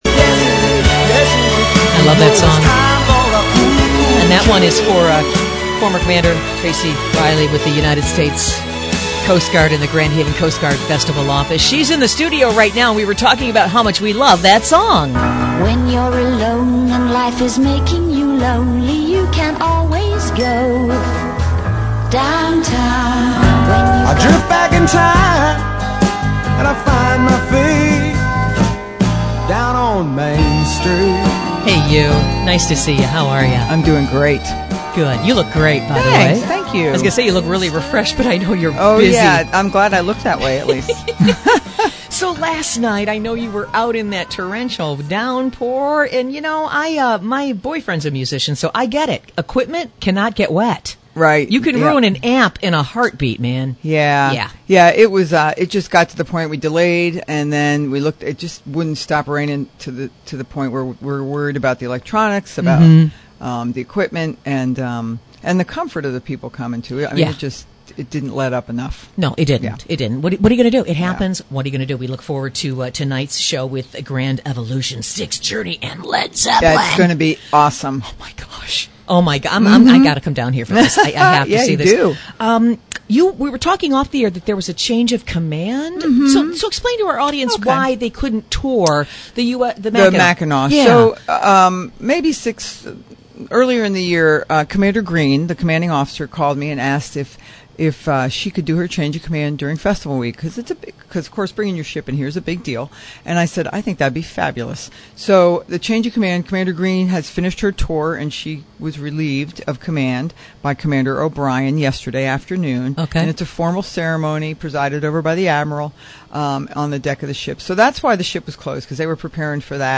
What a fun interview!